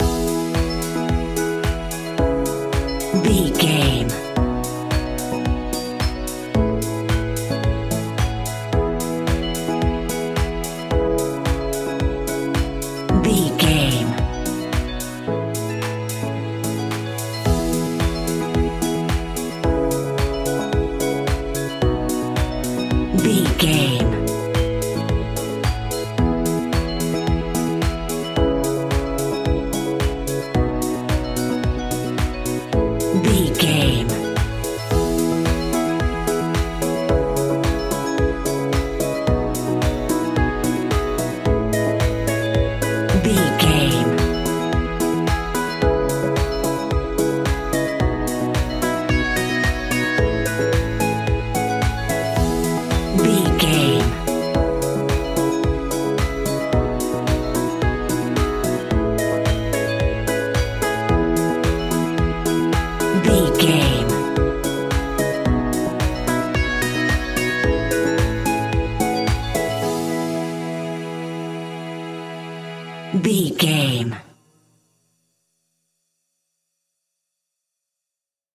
Ionian/Major
peaceful
calm
joyful
electric piano
synthesiser
drums
strings
instrumentals